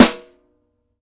CYCdh_K4-Snr06.wav